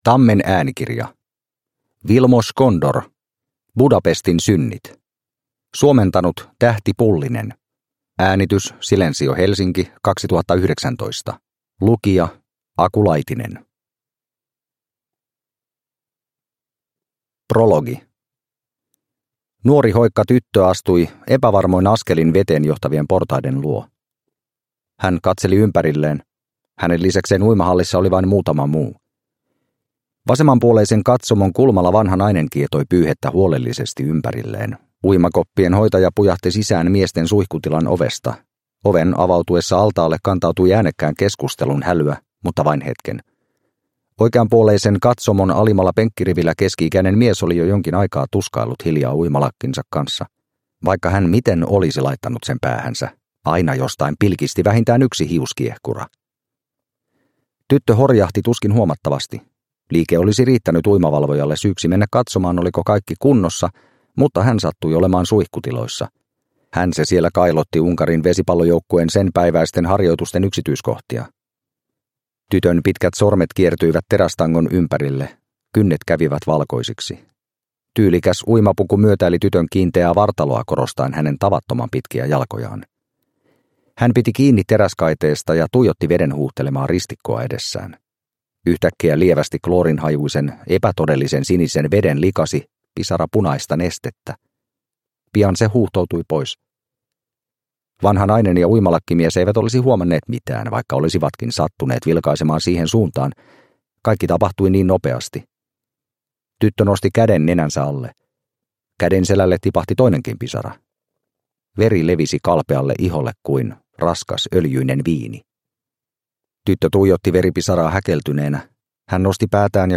Budapestin synnit – Ljudbok – Laddas ner